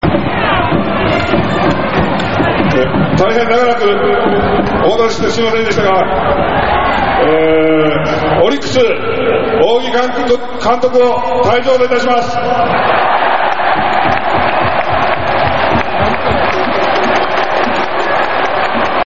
jounai-housou.mp3